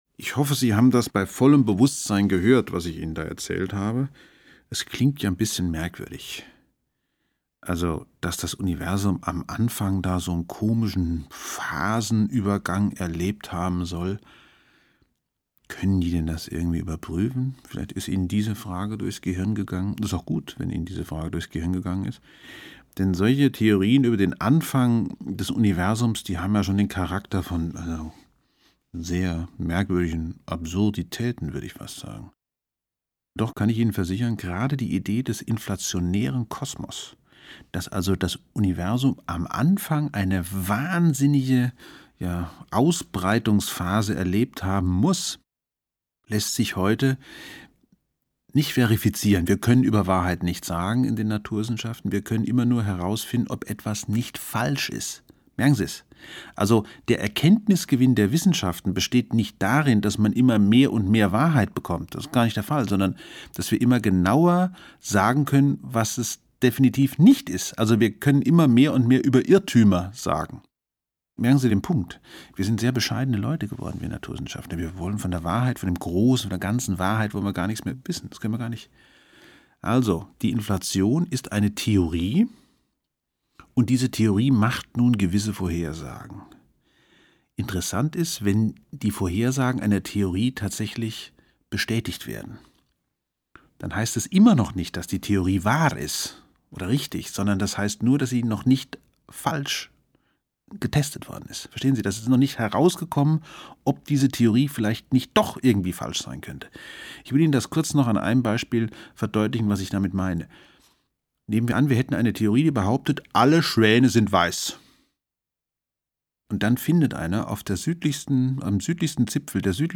Eine Reportage über 13,7 Milliarden Jahre Werden und Vergehen. Ungekürzte Lesung